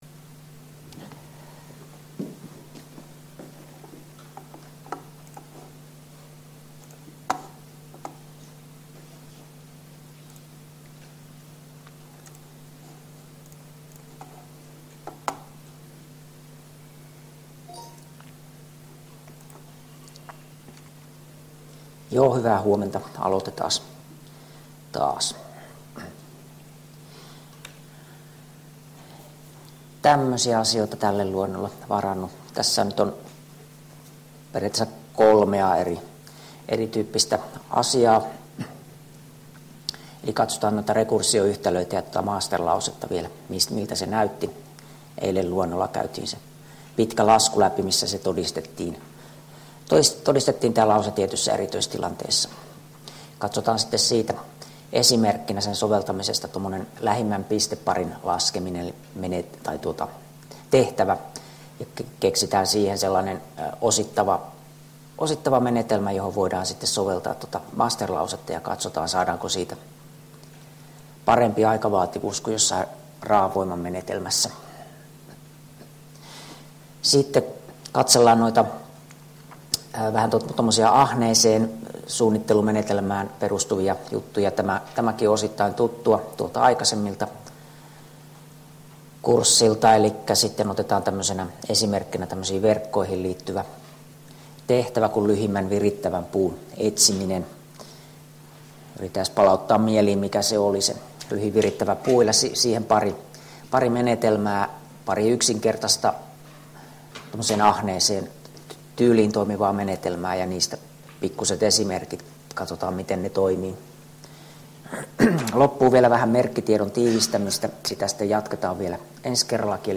Luento 8 — Moniviestin